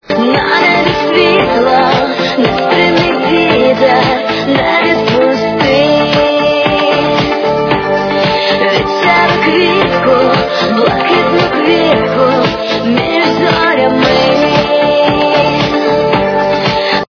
- украинская эстрада
При заказе вы получаете реалтон без искажений.